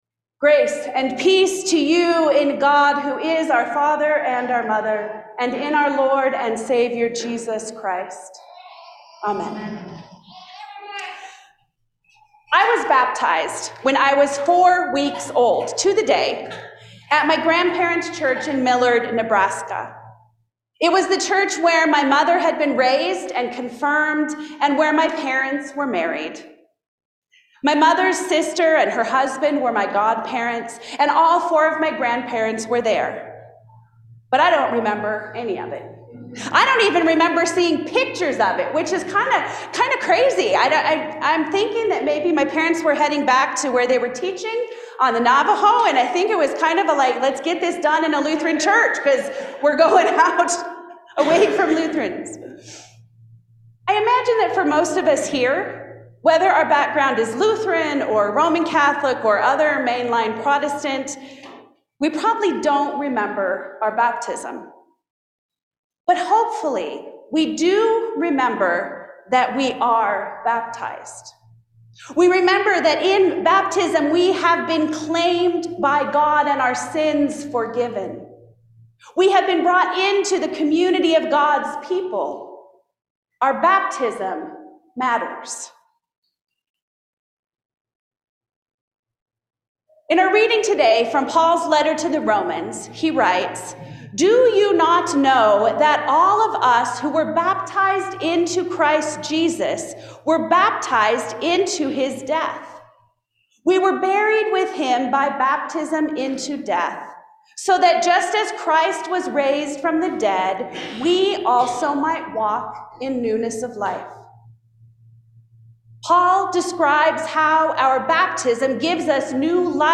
Sermons – Page 26 – All Saints Lutheran Church, ELCA